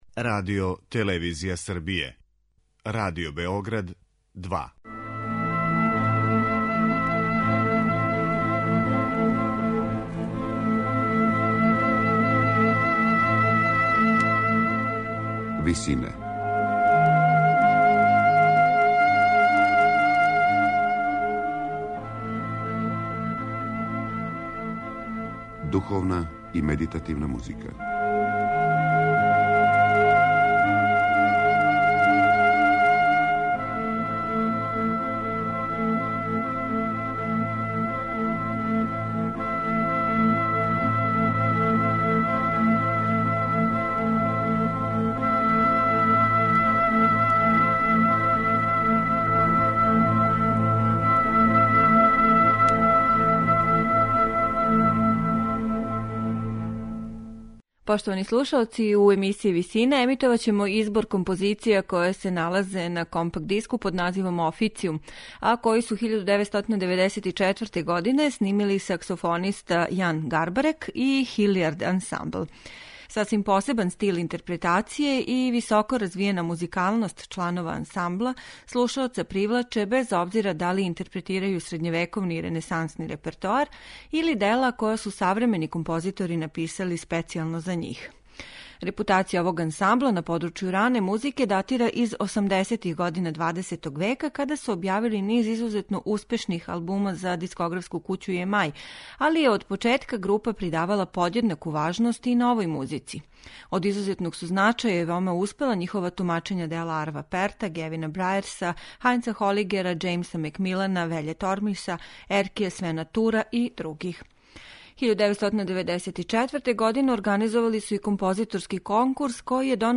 џез саксофониста